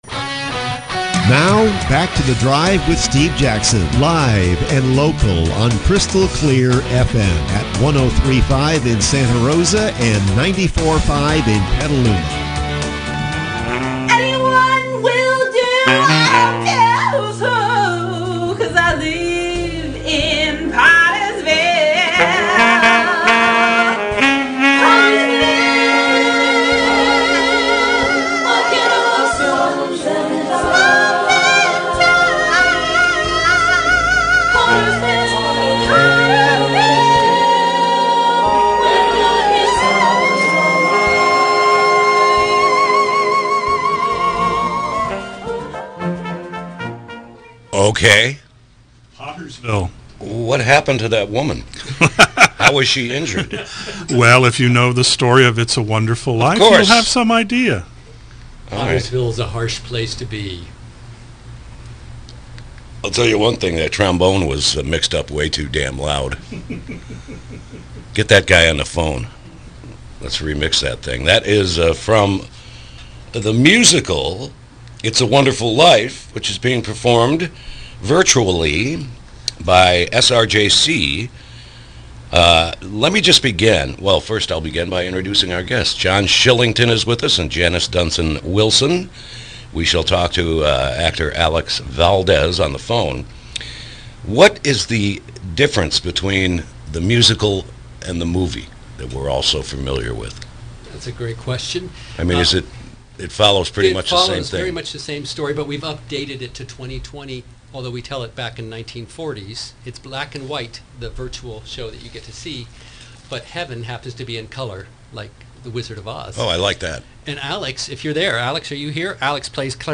KSRO Interview – “It’s a Wonderful Life”